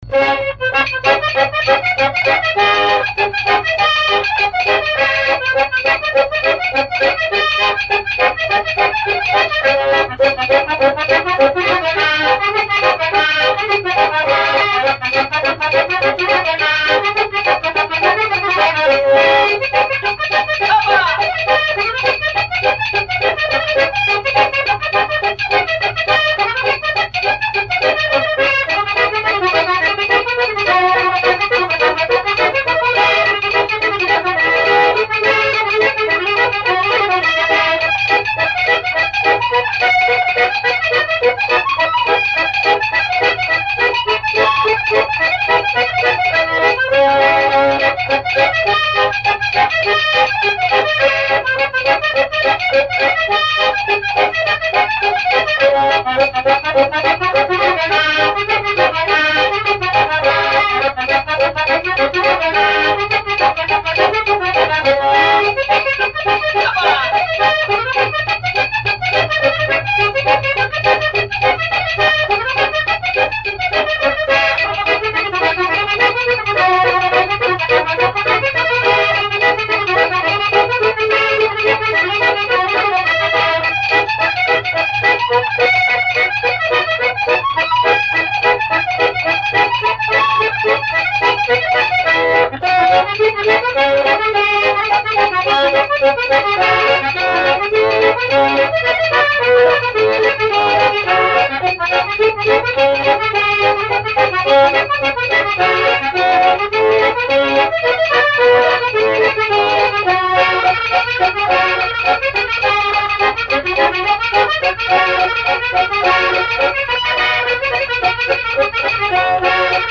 Dantzarako erritmo binarioa.
Sasoiko egotea eskatzen duen dantza bizia.